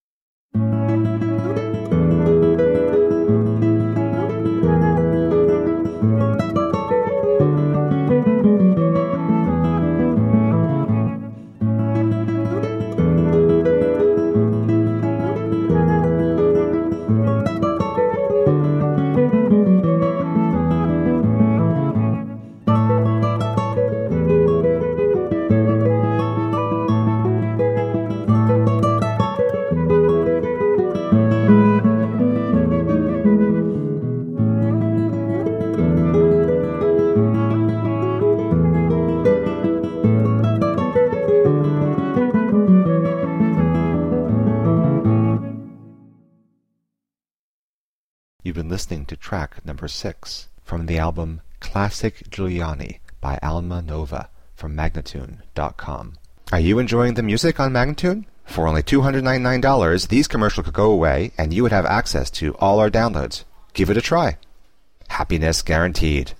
Lively flute/guitar duo.